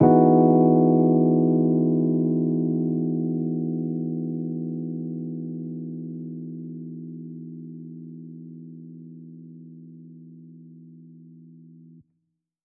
JK_ElPiano2_Chord-Emaj9.wav